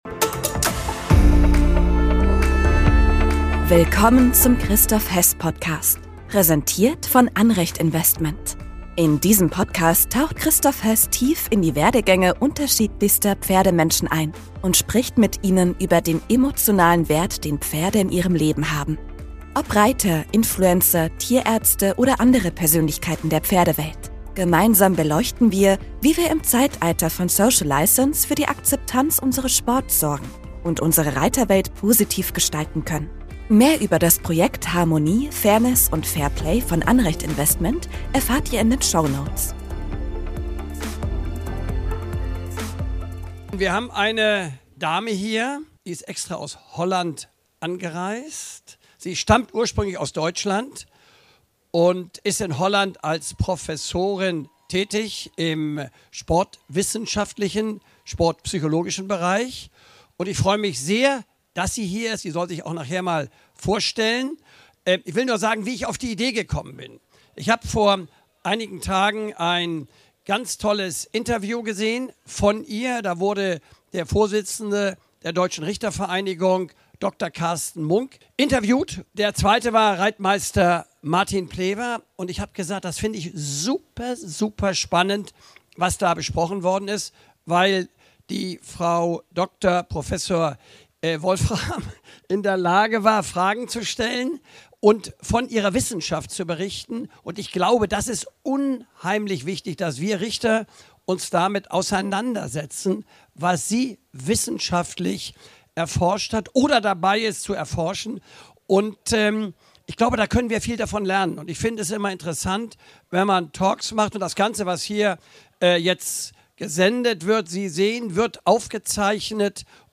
Diese Folge wurde als Live-Podcast bei den Bundeschampionaten in Warendorf im September 2024 aufgezeichnet.